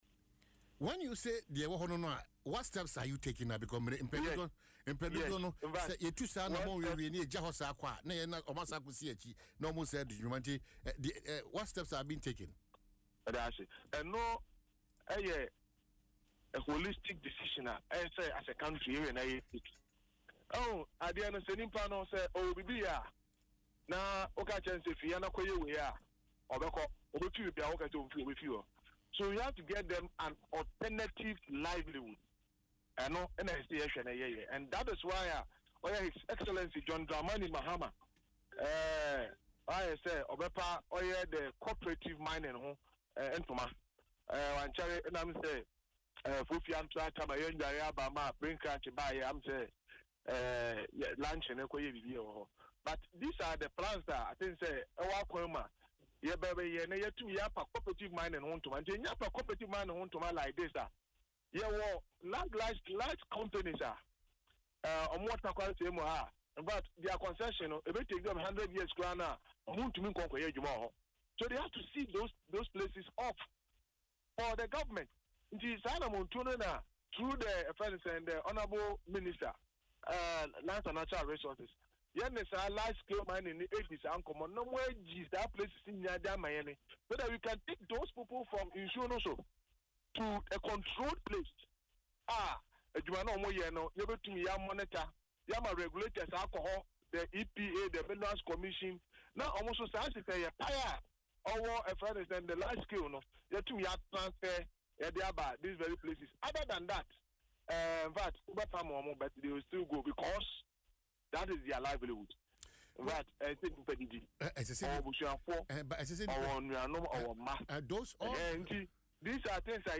Speaking in an interview on Adom FM’s Dwaso Nsem, Mr. Cobbinah emphasised that the fight against galamsey must prioritise alternative livelihoods for the thousands of Ghanaians engaged in small-scale mining.